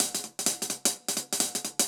Index of /musicradar/ultimate-hihat-samples/128bpm
UHH_AcoustiHatC_128-05.wav